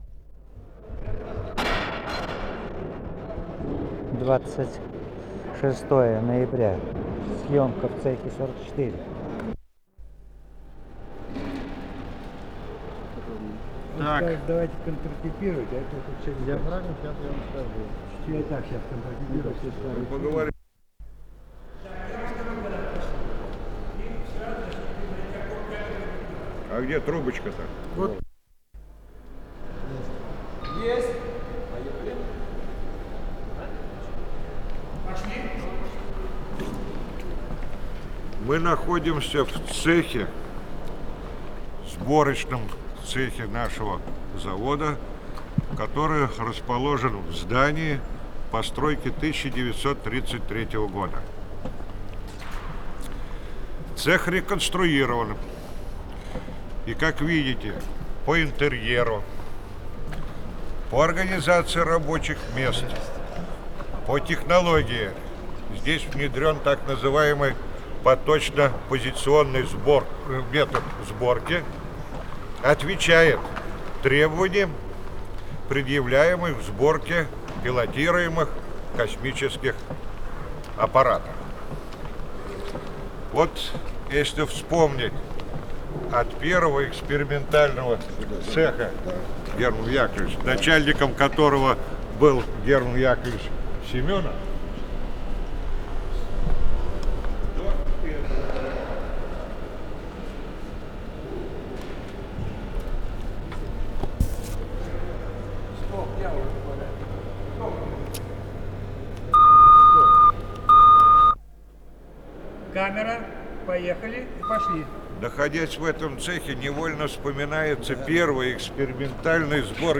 Э-004 — Интервью к 70-летию со дня рождения академика С. П. Королёва — Ретро-архив Аудио